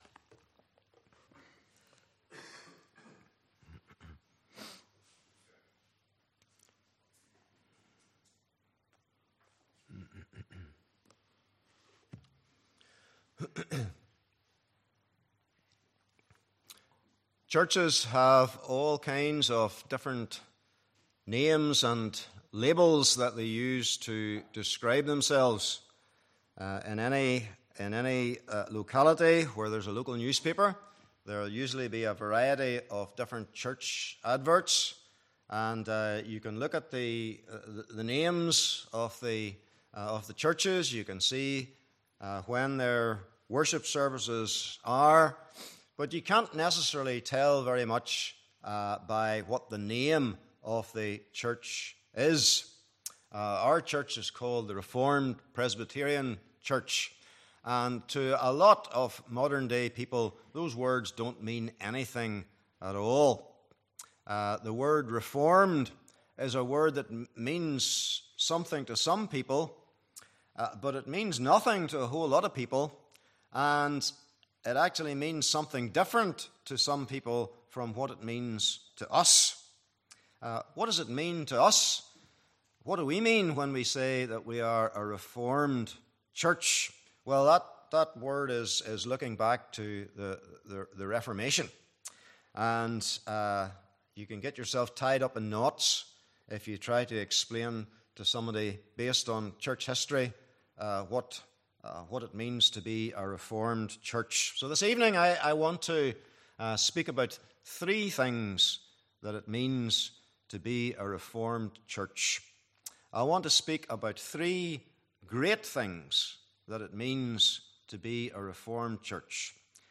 Passage: Ephesians 1:1-2:10 Service Type: Evening Service